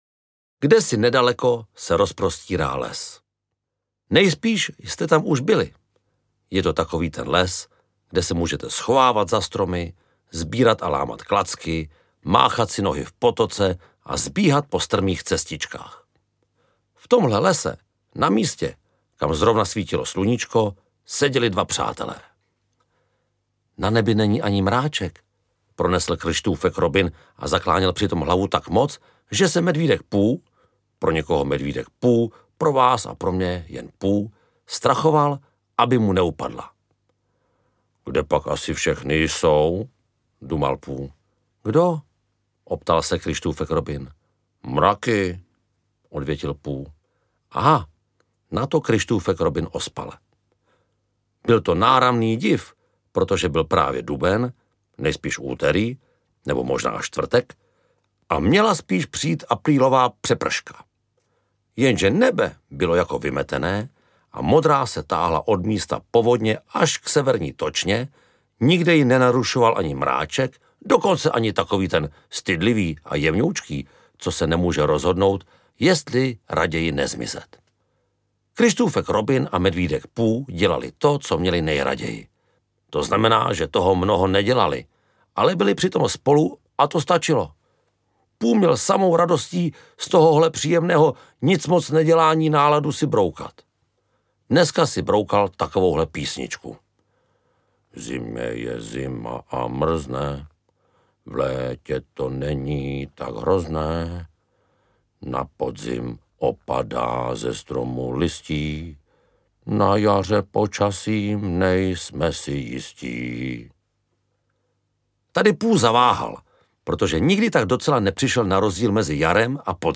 Ukázka z knihy
Čte Jakub Kohák.
Vyrobilo studio Soundguru.